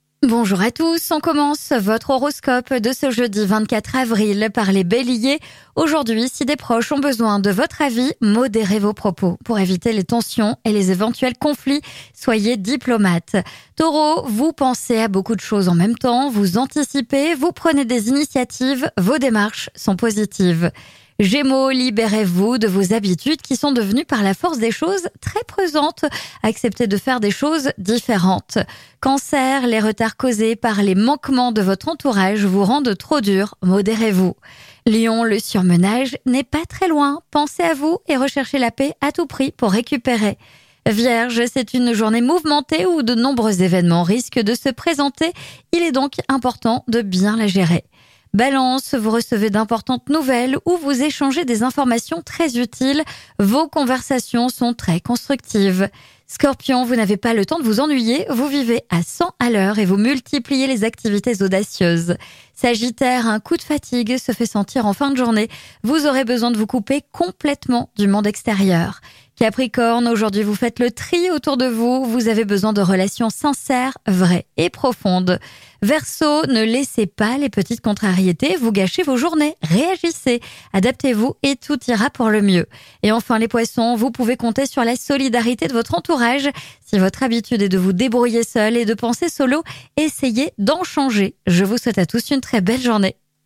1 [Prédication] Vendredi 26 avril 2024 : Jn XIV, 1-6